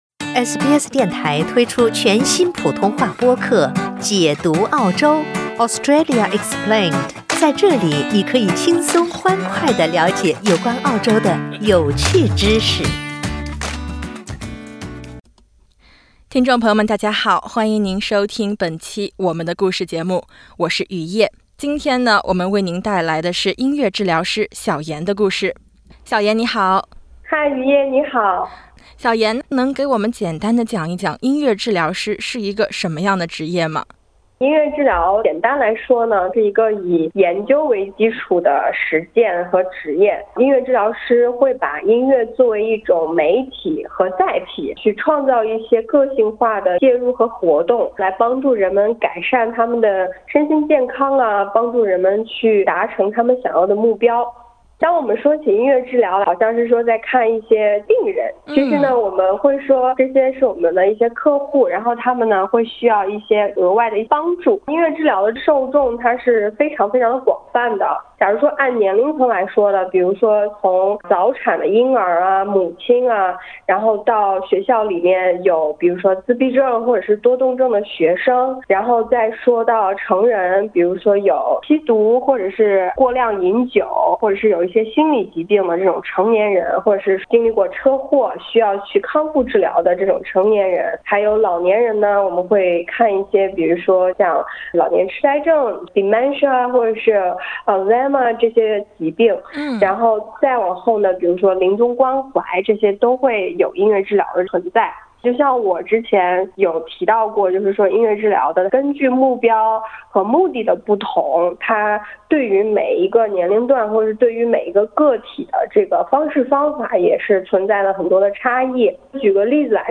欢迎点击图片音频，收听完整采访。